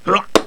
daemon_attack6.wav